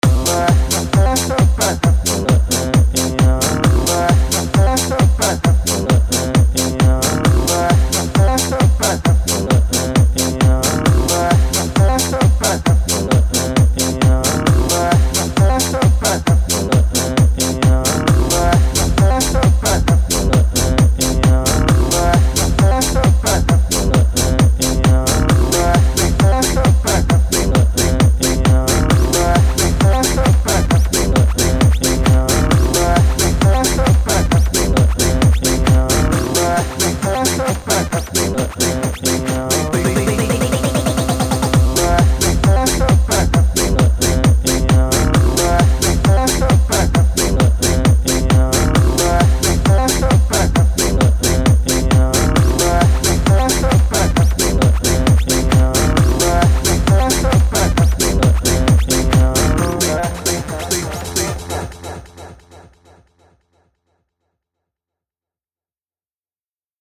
Tags: game show sounds